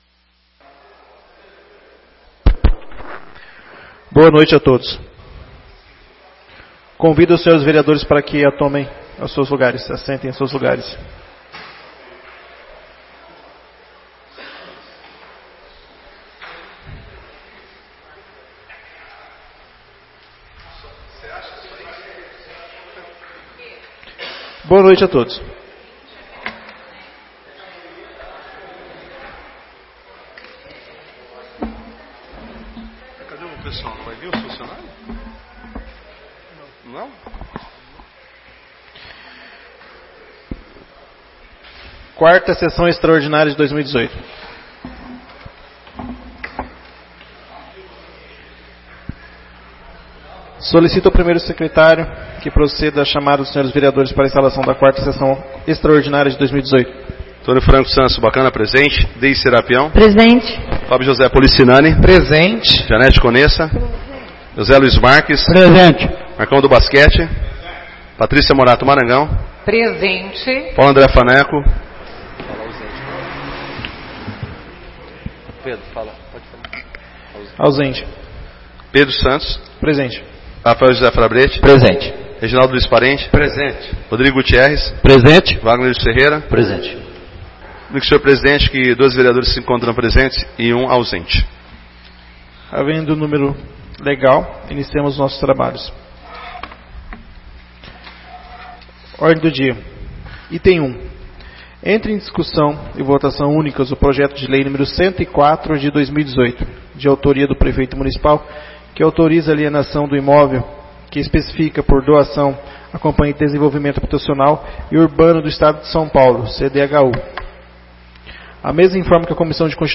4ª Sessão Extraordinária de 2018 — Câmara Municipal de Garça